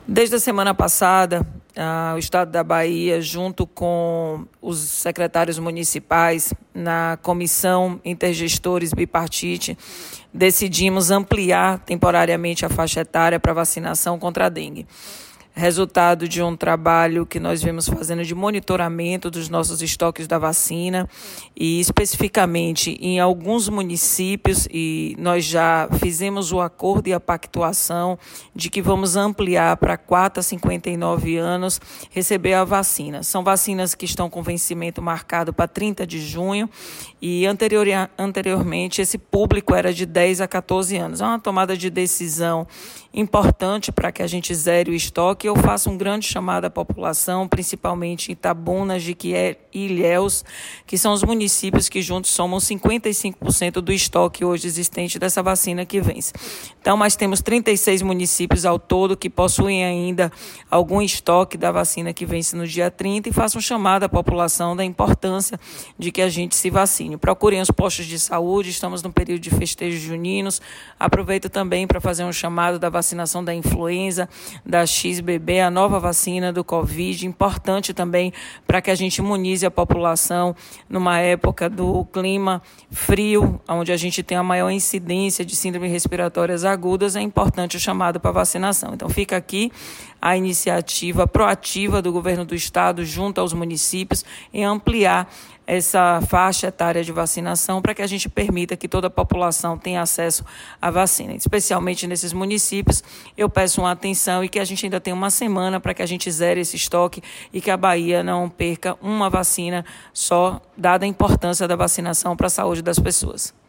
A secretária da Saúde do Estado da Bahia, Roberta Santana, faz um apelo aos municípios e à sociedade